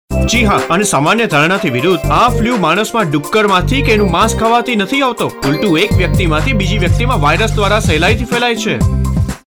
Gujarati Voice Over Artists Samples
Gujarati Voice Over Male Artist 2
CS_GUJRATI_M_02.mp3